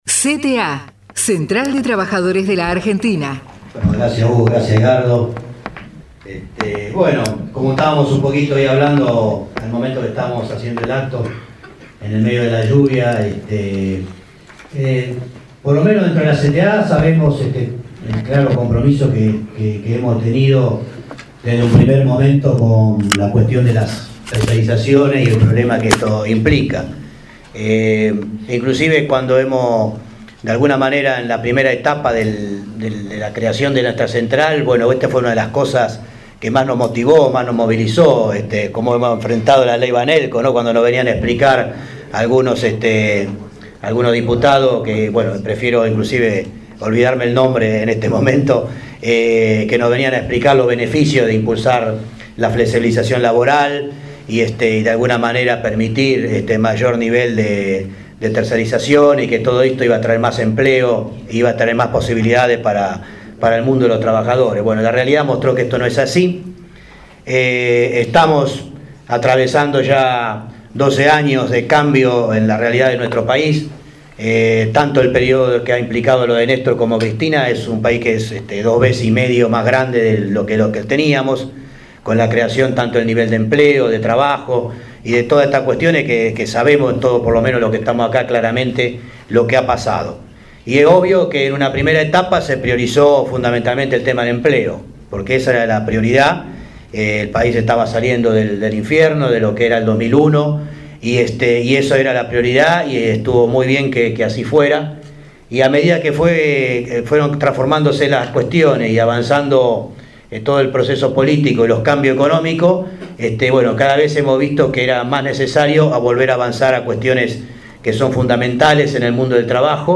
AUDIENCIA PÚBLICA y RADIO ABIERTA en el CONGRESO NACIONAL